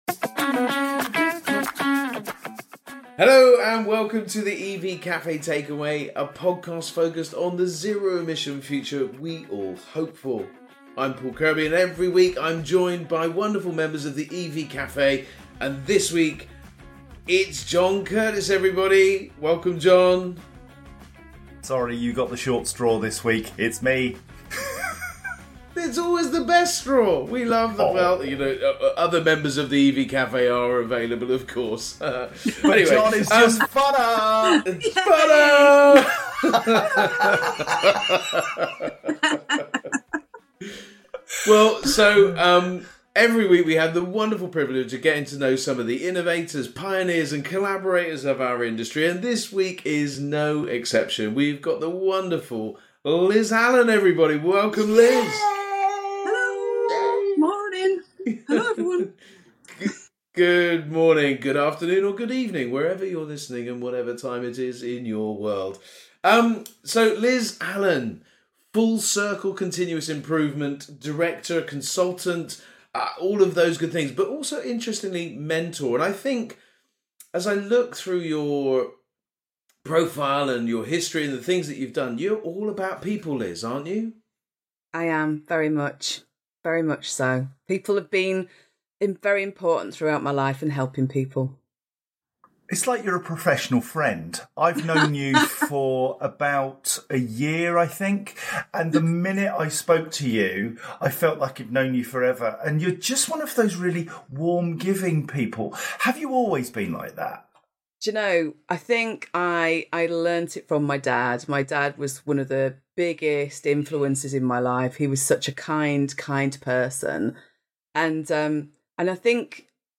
We even get a bit of a singalong! Life’s not all a bunch of roses though and we hear how life’s toughest events are a lesson for the future!